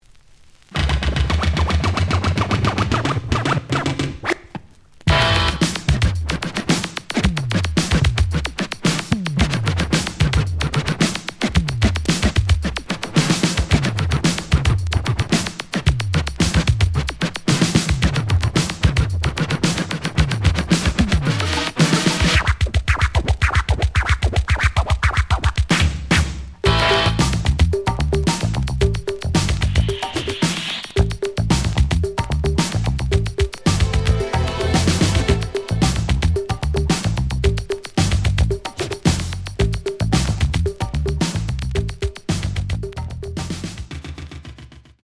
The audio sample is recorded from the actual item.
●Format: 7 inch
●Genre: Funk, 80's / 90's Funk